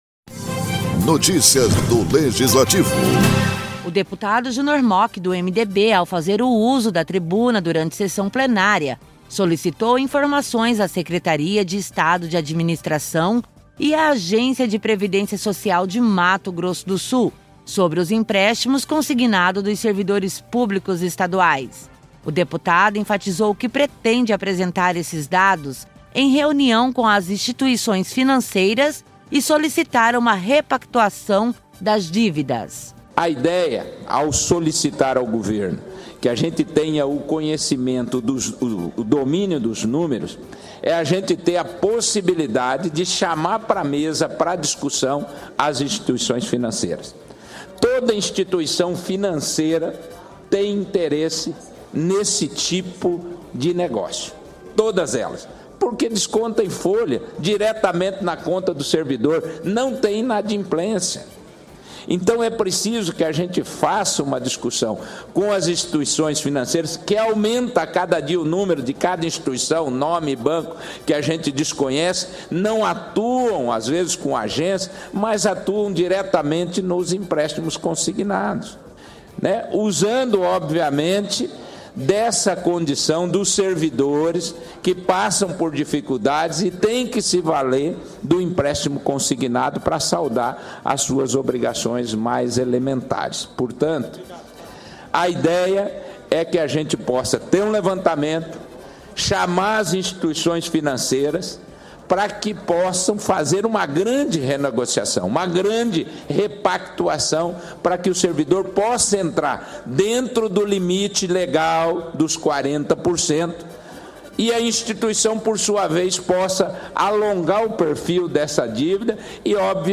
Na tribuna, o parlamentar explicou que a ideia é apresentar esses dados em reunião com as instituições financeiras e solicitar uma repactuação das dívidas.
Produção e Locução